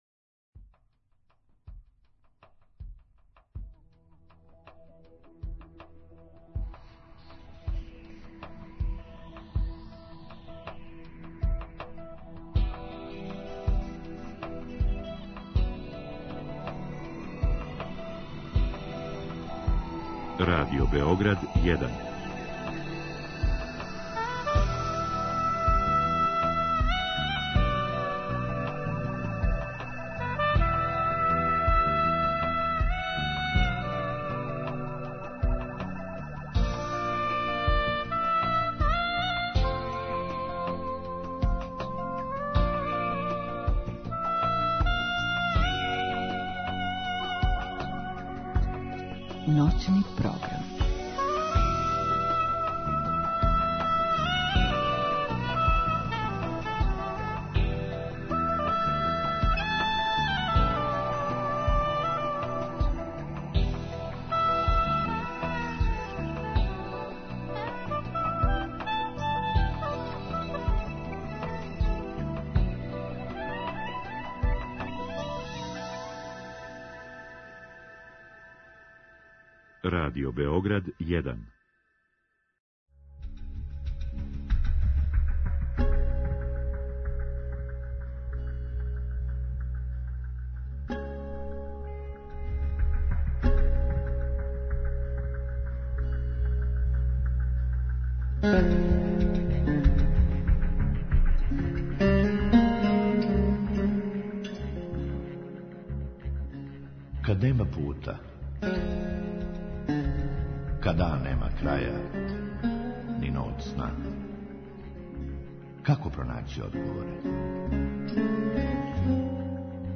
У другом сату слушаоци госту могу поставити питање у директном програму или путем Инстаграм странице емисије.